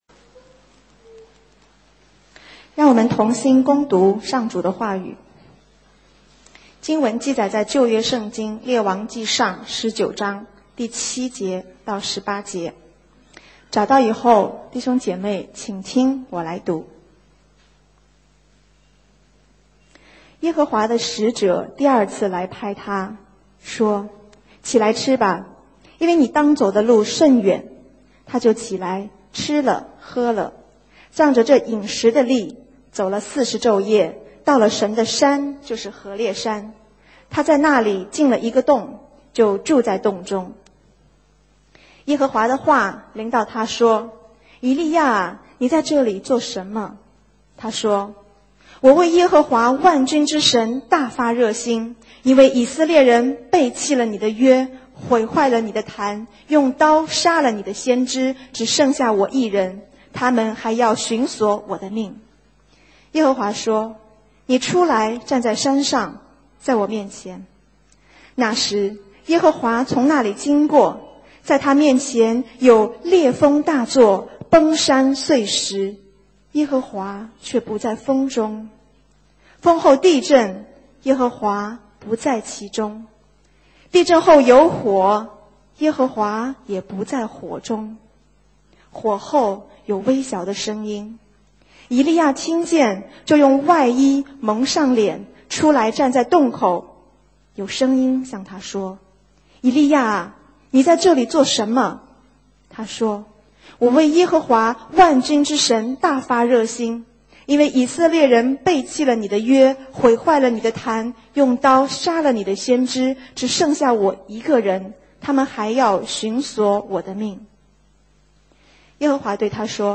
讲章：门徒的忠心(2010年10月3日，附音频)
因为我讲话不太清楚，需要有人帮我翻译（传话）。